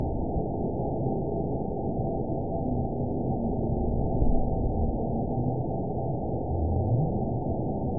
event 917865 date 04/19/23 time 10:40:32 GMT (2 years ago) score 8.69 location TSS-AB04 detected by nrw target species NRW annotations +NRW Spectrogram: Frequency (kHz) vs. Time (s) audio not available .wav